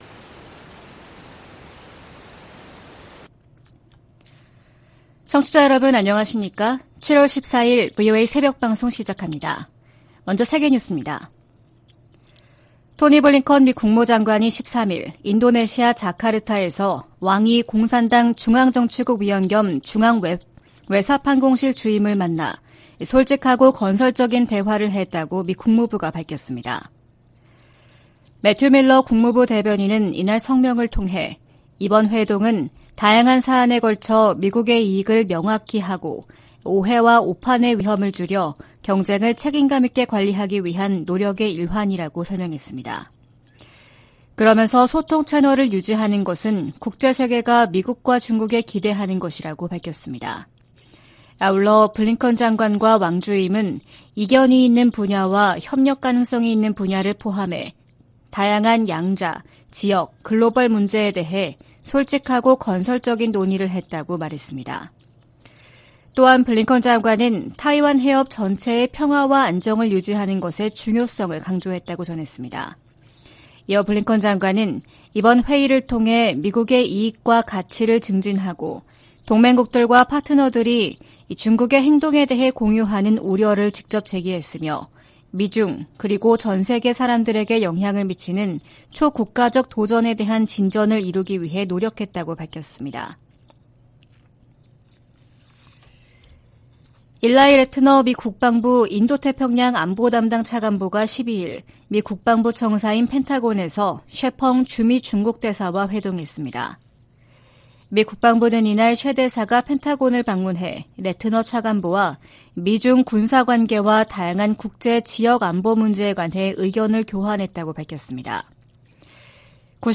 VOA 한국어 '출발 뉴스 쇼', 2023년 7월 14일 방송입니다. 13일 북한 당국이 전날(12일) 발사한 탄도미사일이 고체연료 대륙간탄도미사일(ICBM) 화성-18호라고 밝혔습니다. 미국 정부와 의회, 유엔과 나토 사무총장, 한일 정상, 미한일 합참의장이 북한 정권의 장거리탄도미사일 발사를 강력히 규탄하며 대화에 나설 것을 촉구했습니다. 북한 주민들에게 자유와 진실의 목소리를 전해야 한다고 미국 의원들이 강조했습니다.